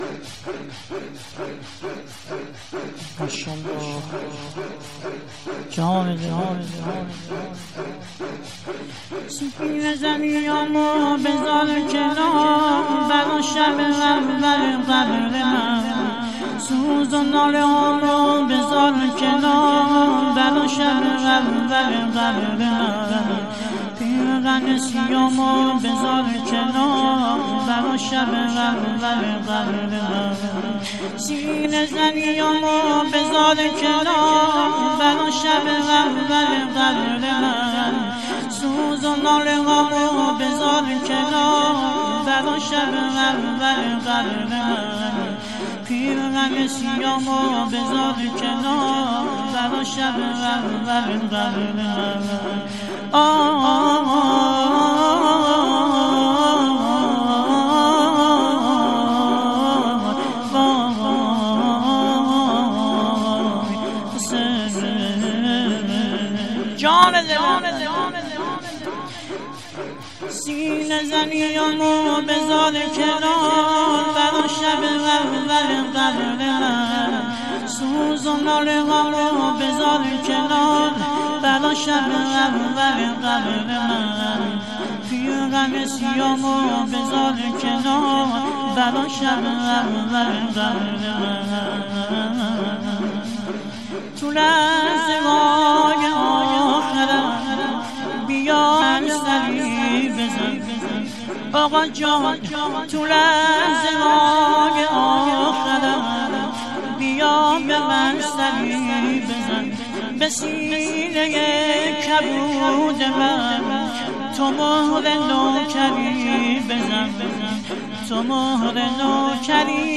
سینه زنی هامو بزار کنار